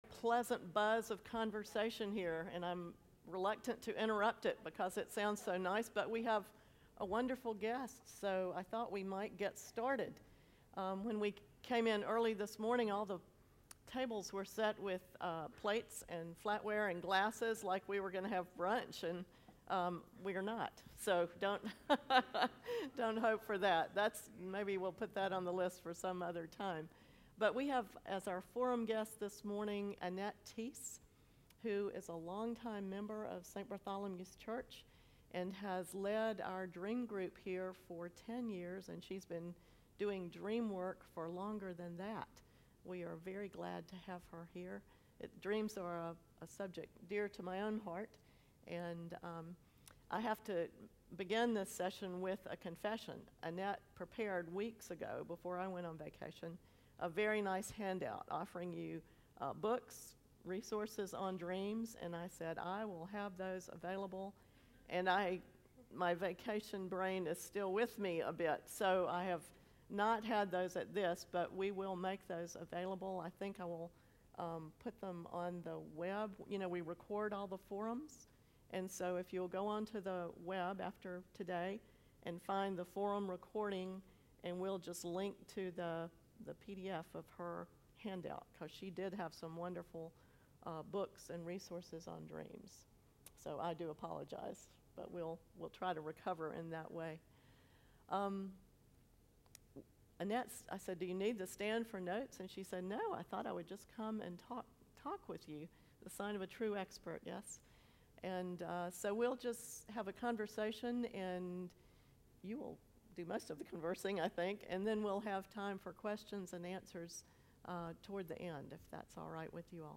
St.Bart's Dream Forum
sermon